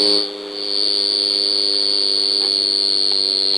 The Cicada Song
When sing, Small Bottle Cicada sings for about 4 seconds, then stop for tens of seconds for another call.
Sound of Small Bottle Cicada.
From the waveform analyses, the calling song of  Small Bottle Cicadas has the carrier frequency of 4.4KHz and 8.8KHz harmonic, modulated with 100 pulses per second.
SmallBottelCicada1.wav